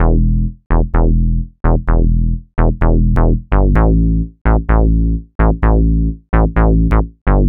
Retro Bass.wav